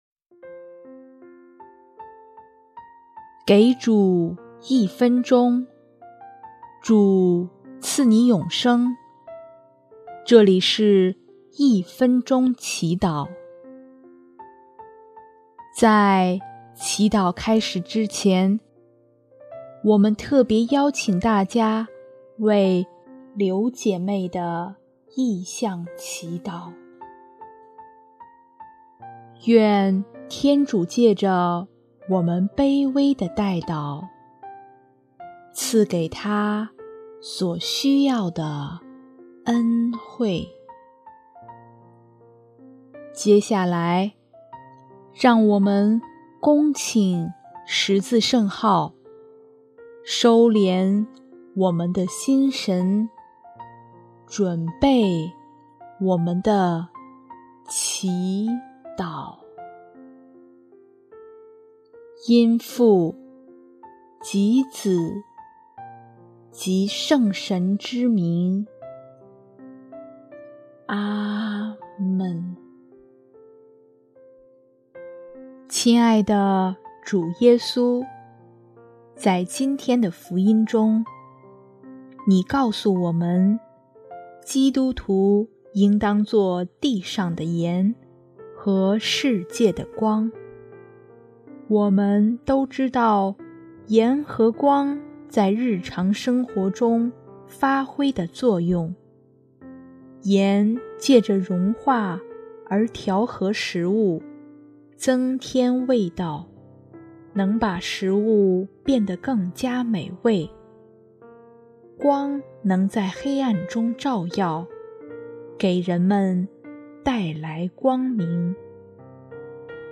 音乐： 第三届华语圣歌大赛参赛歌曲《成光做盐》